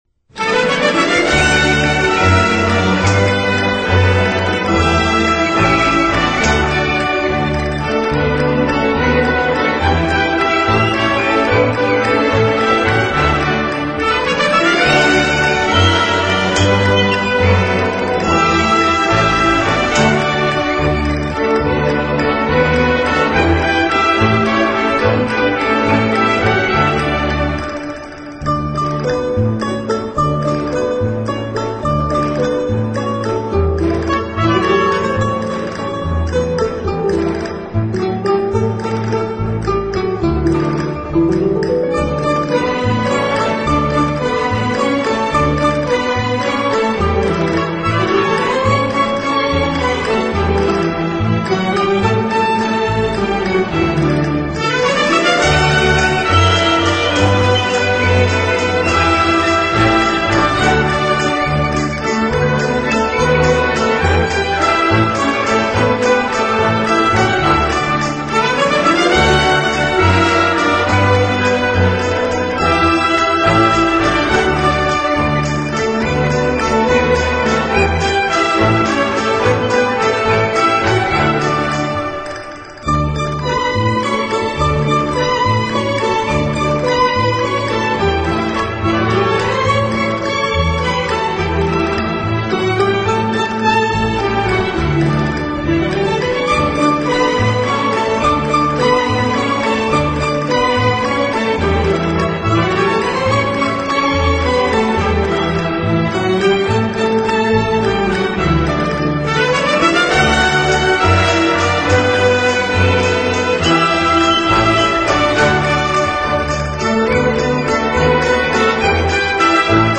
音乐类型：Classic 古典
音乐风格：Classical,Waltz